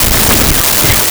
Sweep1
sweep1.wav